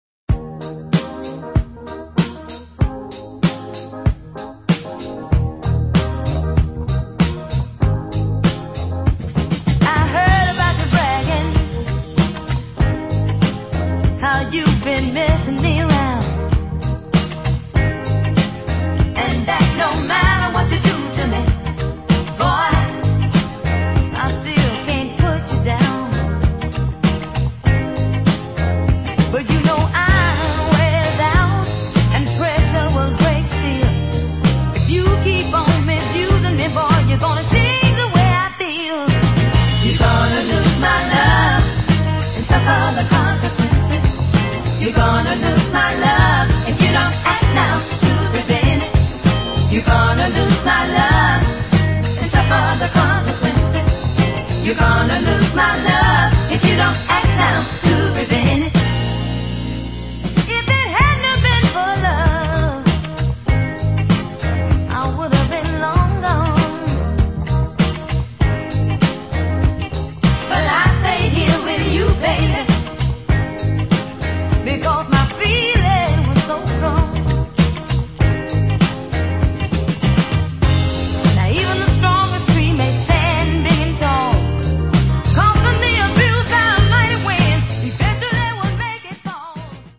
modern & northern soul music on 45